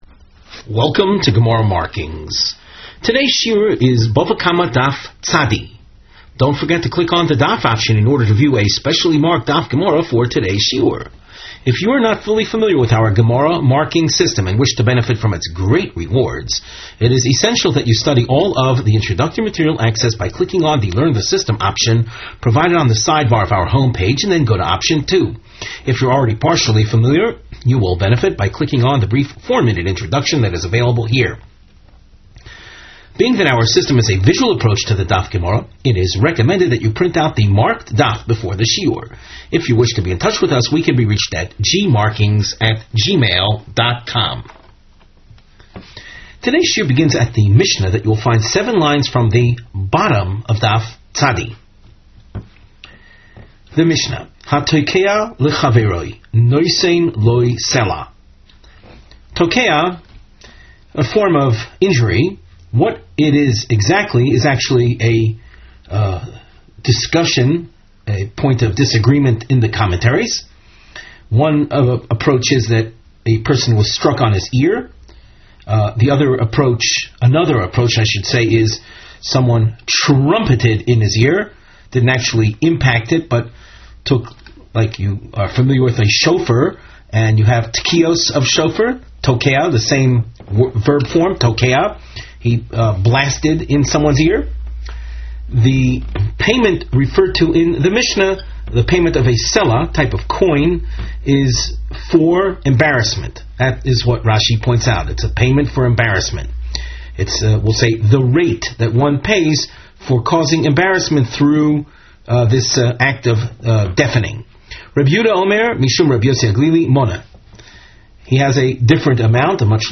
Please click on the above video to hear the Rav give the shiur.